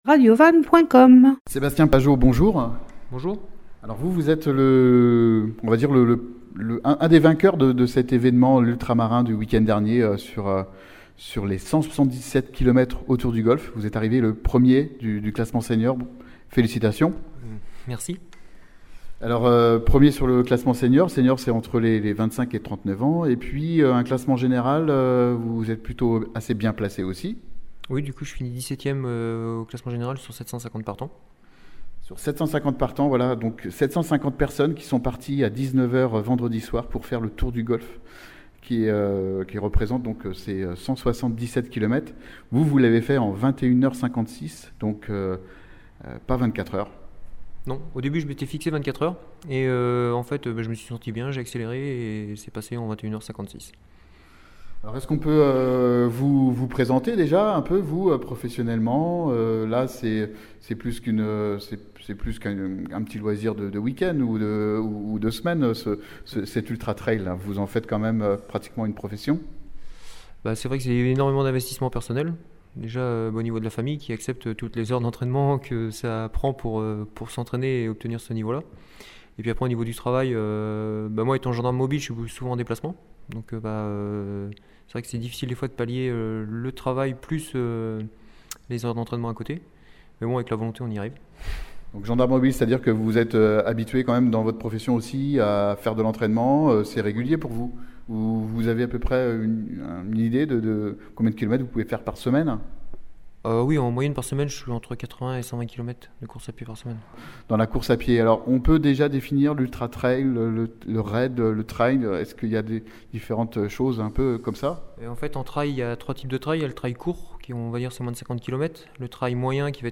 Ecoutez l’interview d’un champion de l’Ultra Raid du Golfe du Morbihan.
Interview